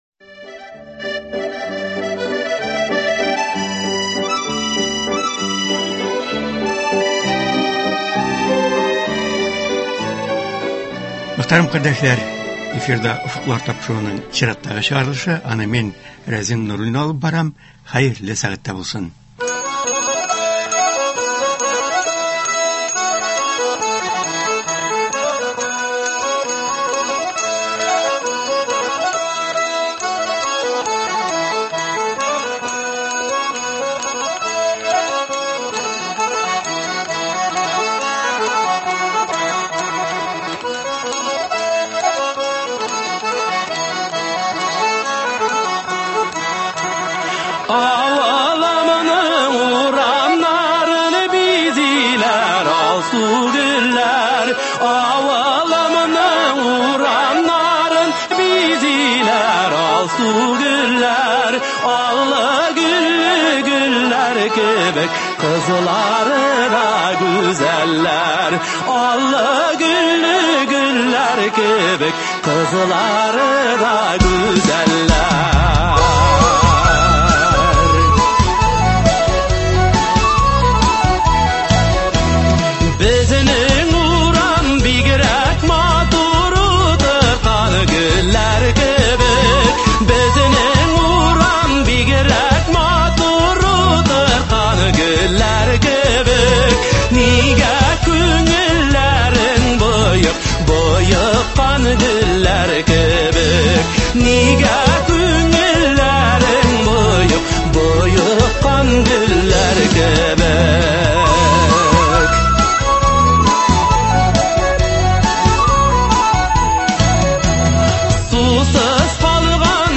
Республикабыз авыл эшчәннәре кышка әзерләнә: кырларда уңыш җыю һәм чәчү эшләре төгәлләнеп килә, терлекчелектә кышкы рационга күчәләр, базарларда көзге ярминкәләр дәвам итә. Быелгы ел үзенчәлекләре турында Татарстан авыл хуҗалыгы һәм азык-төлек министрының беренче урынбасары Ленар Наил улы Гарипов сөйли, алдынгы һәм артта калган хуҗалыклар, аларда башкарылган эшләр турында мәгълүмат бирә.